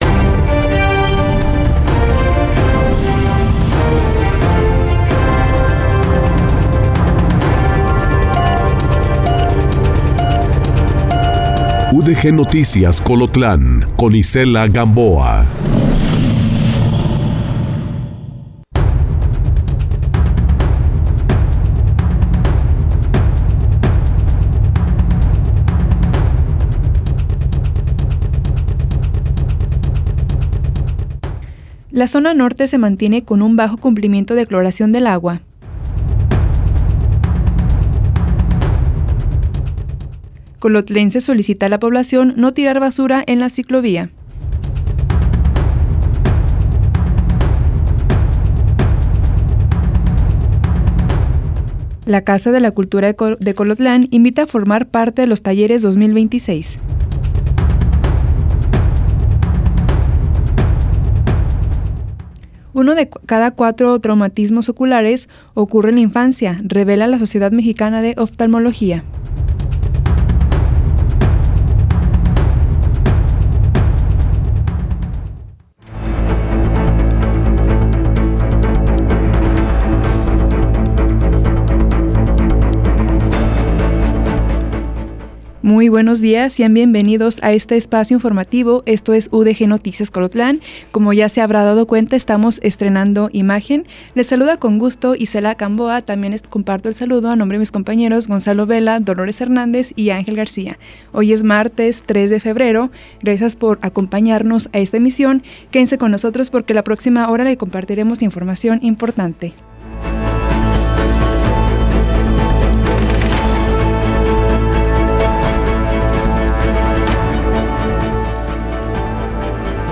En este noticiero, nos enfocamos en las noticias locales que afectan directamente su vida y su entorno. Desde políticas y eventos comunitarios hasta noticias de última hora y reportajes especiales.